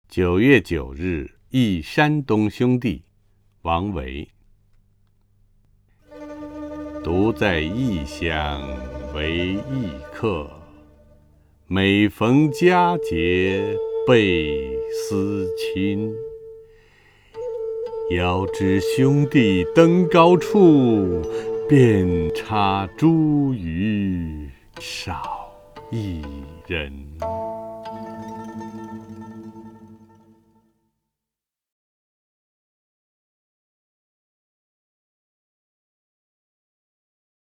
陈醇朗诵：《九月九日忆山东兄弟》(（唐）王维) (右击另存下载) 独在异乡为异客， 每逢佳节倍思亲。